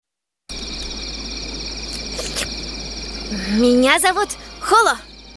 Даже в этом отрывке слышно насколько ру локализация лучше оригинала.